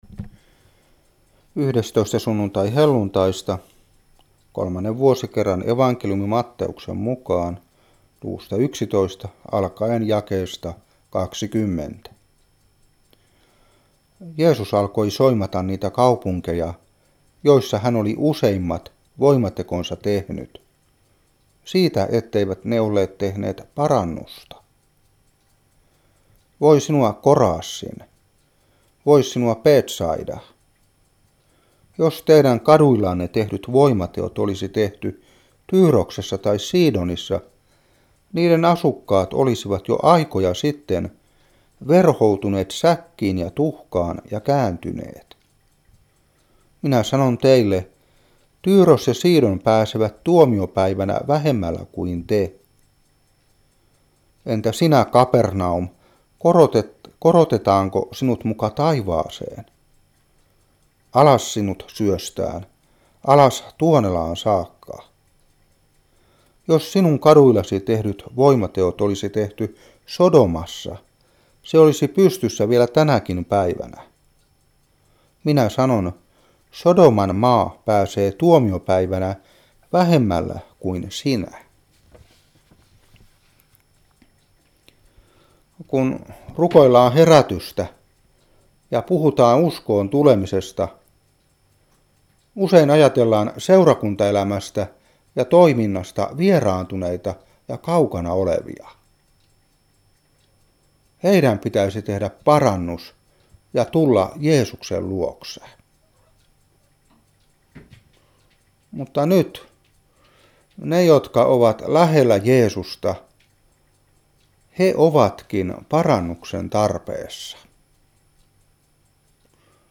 Saarna 2015-8.